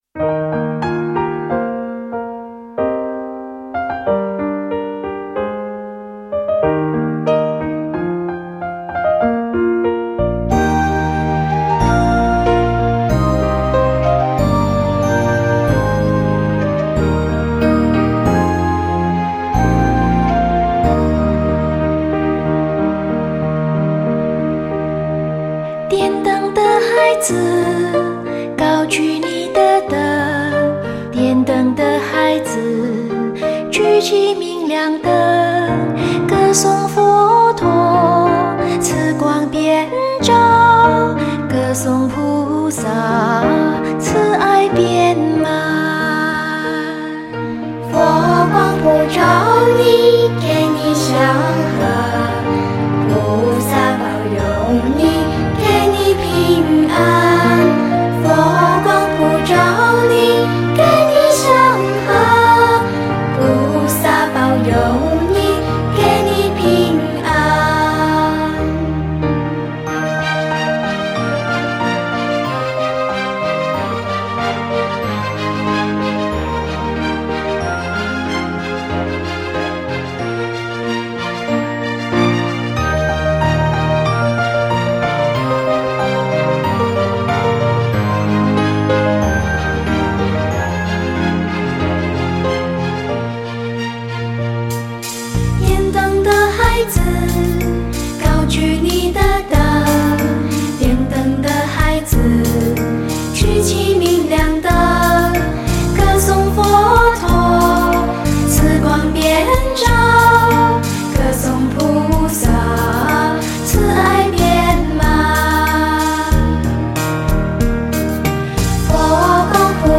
清纯童声在温柔女声的引领下，唱出了童心中最美好的单纯、快乐与善良，宛如清
儿童佛曲佛国小脚丫畅销姐妹作，清晰、甜美最让心花开的儿童佛曲专辑，天使童声，带来幸福微笑！